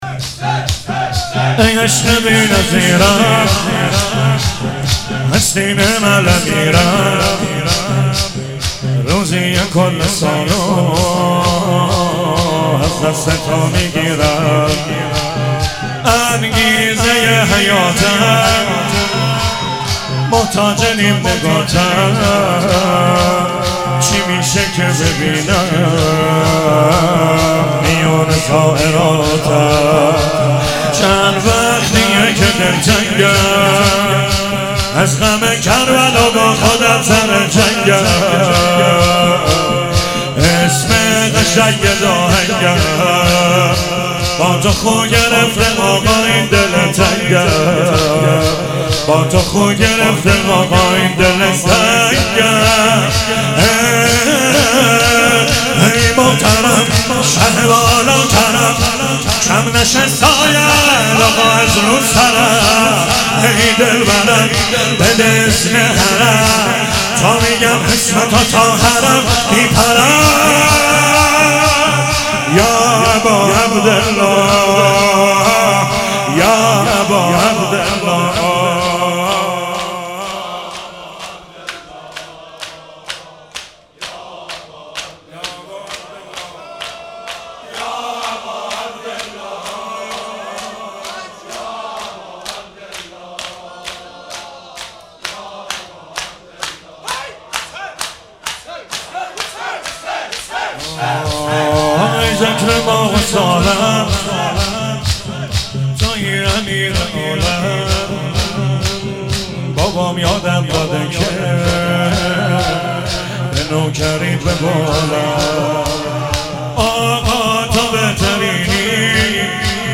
ولادت امام رضا علیه السلام